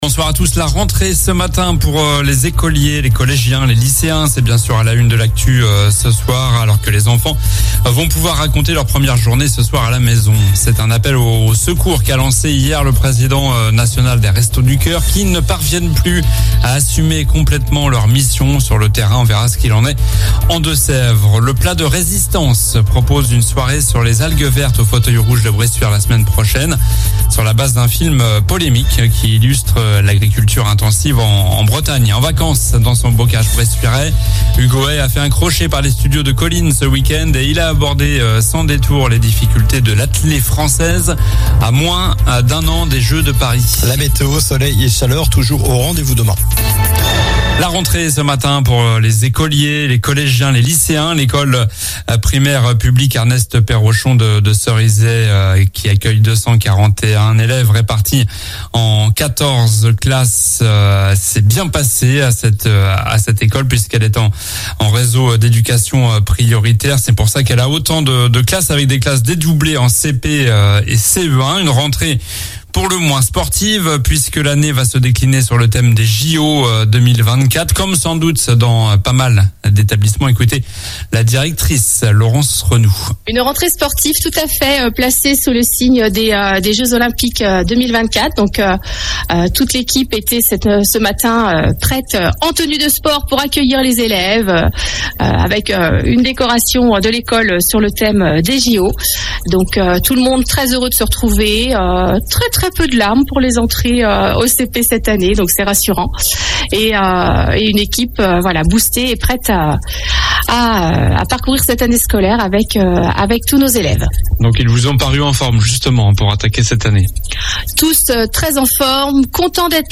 Journal du lundi 4 septembre (soir)